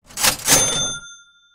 cha-ching.mp3